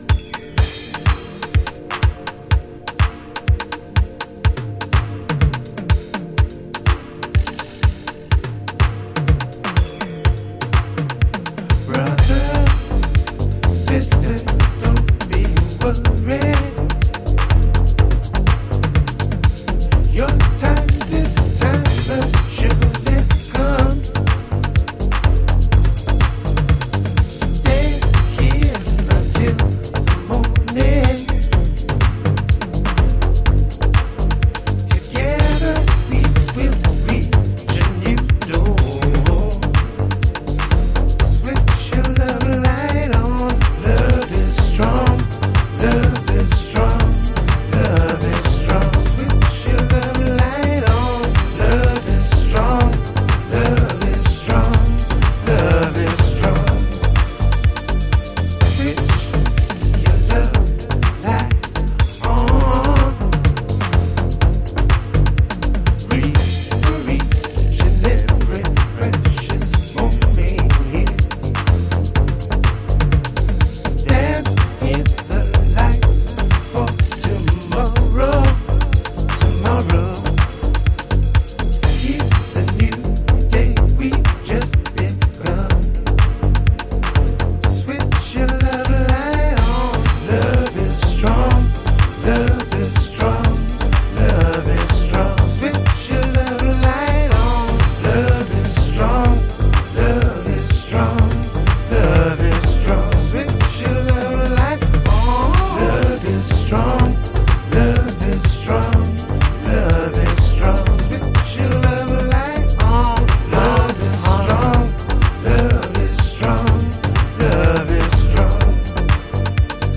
Chicago Oldschool / CDH
Original Mix